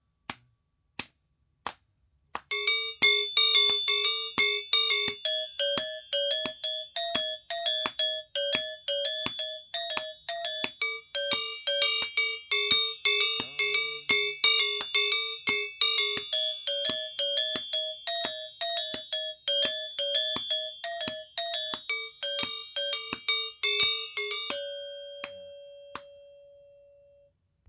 I play Balinese gamelan music in Los Angeles as a member of Sanggar Tujunga.
Rehearsal Recordings